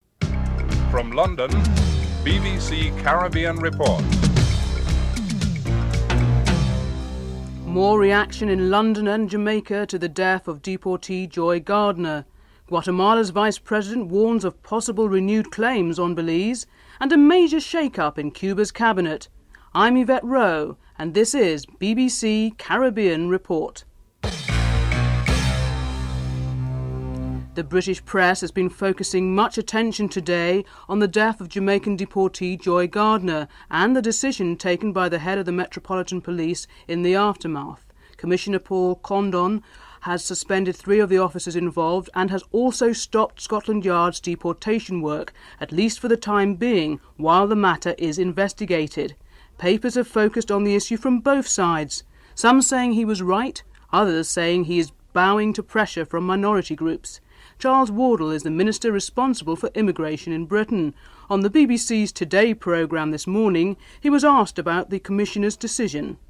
1. Headlines (00:00-00:32)